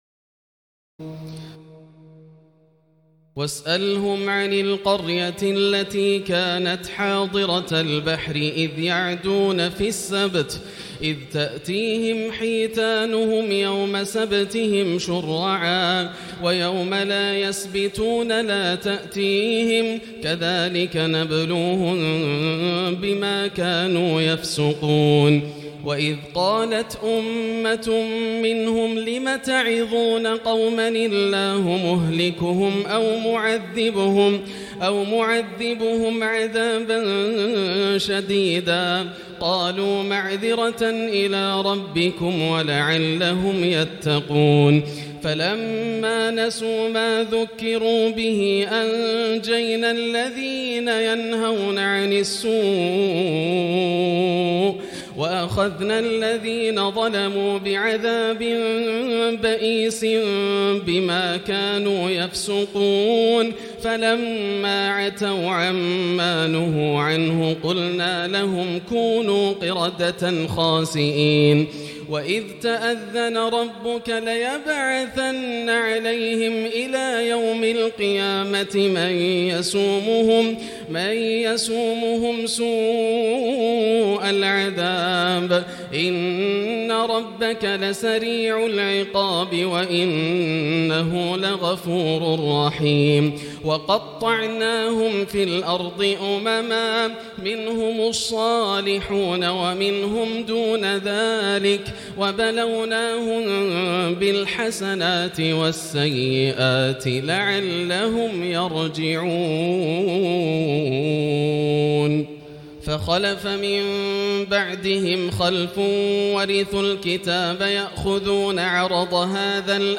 تراويح الليلة الثامنة رمضان 1439هـ من سورتي الأعراف (163-206) والأنفال (1-40) Taraweeh 8 st night Ramadan 1439H from Surah Al-A’raf and Al-Anfal > تراويح الحرم المكي عام 1439 🕋 > التراويح - تلاوات الحرمين